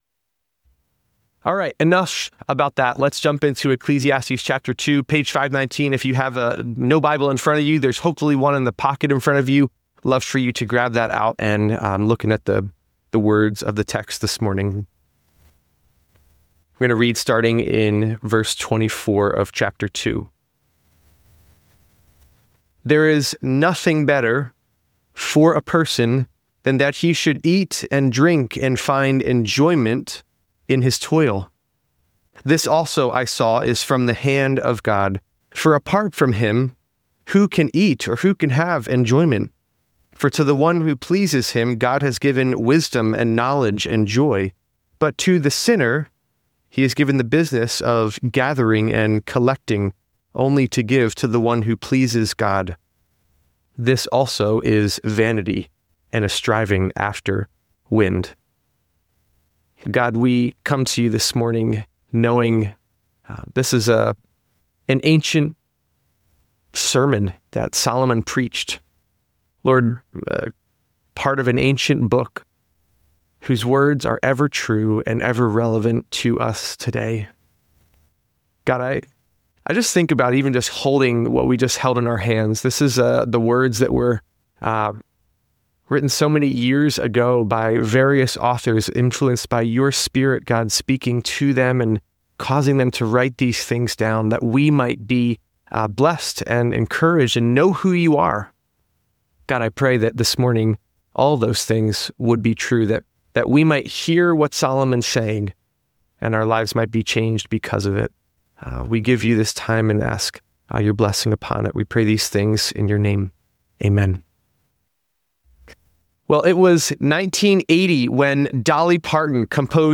It contrasts the repetitive, dissatisfied cycle of pursuing happiness “under the sun” with a “with God” life that receives wisdom, knowledge, and joy, warning against treating God’s gifts as ultimate sources of fulfillment. The speaker offers practical encouragement to enjoy everyday meals and daily work with gratitude and worship. An interview with Olympian Kelsey Worrell illustrates processing disappointment, finding joy in ordinary faithfulness, and grounding identity in Christ rather than achievements. The message concludes with instructions and reflection on communion as remembrance of Jesus’ once-for-all sacrifice.